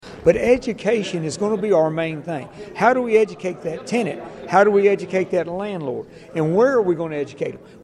The Human Rights Commission of Hopkinsville–Christian County hosted its second public forum on the Uniform Residential Landlord–Tenant Act.